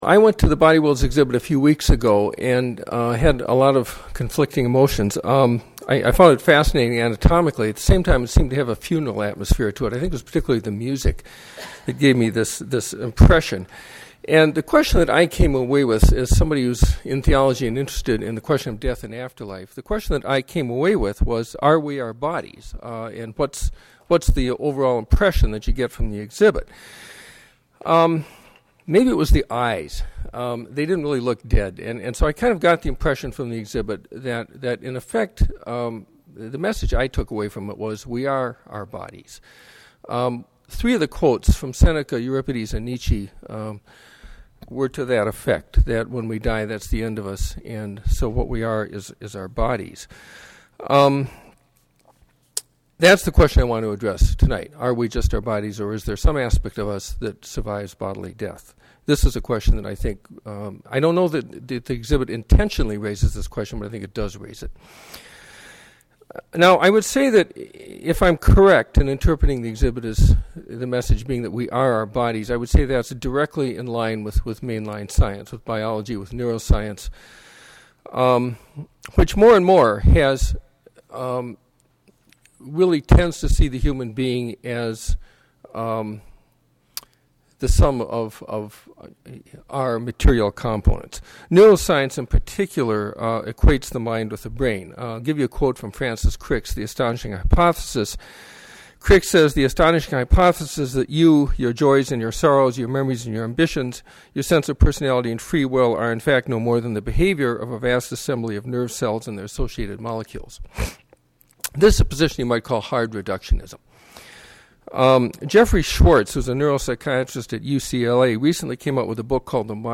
Lecture Audios